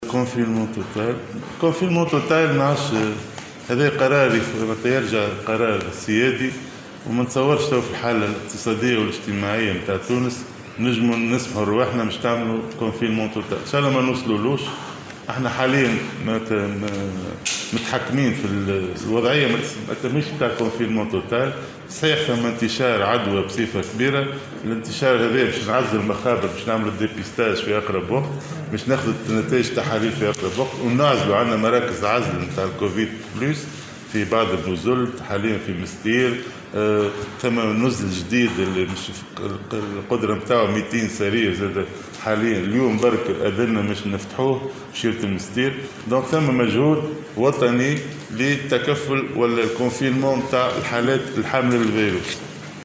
بيّن وزير الصحة فوزي مهدي في رده على أسئلة النواب في لجنة الصحة بمجلس نواب الشعب...